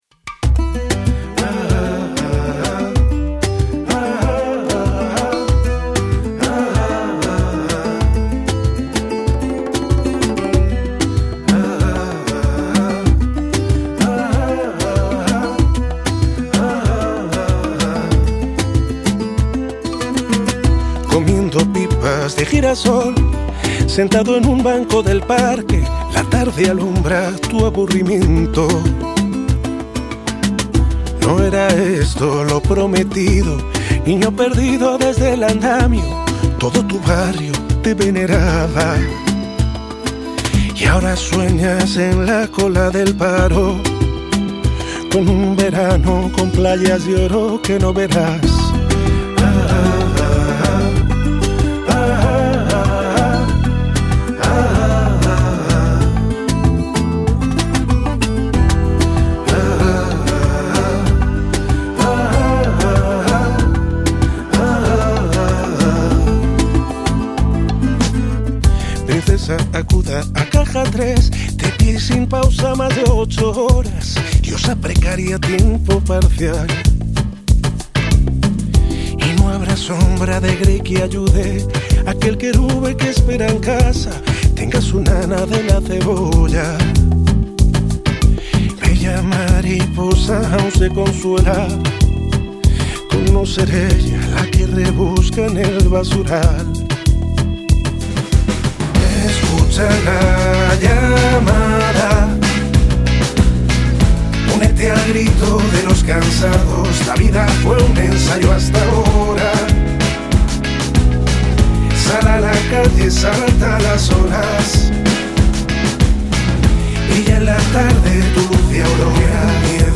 converte os sons do carnaval do Uruguay nun himno global